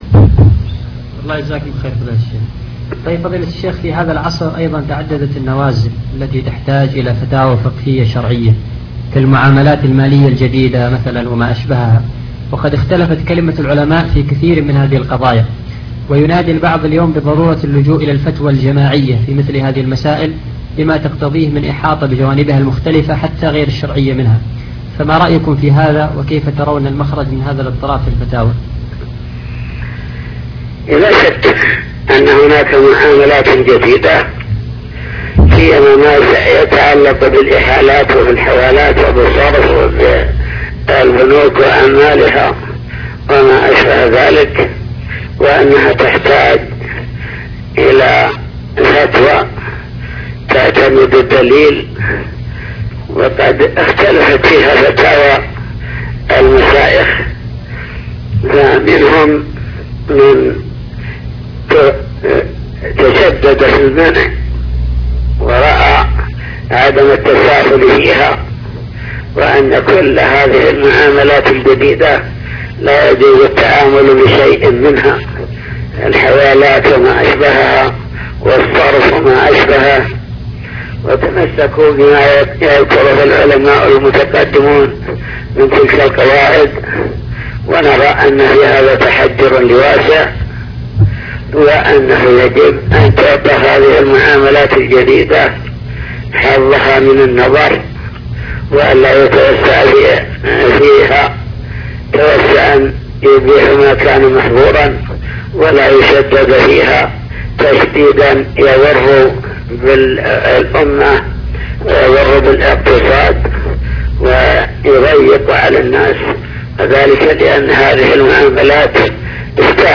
حوار هاتفي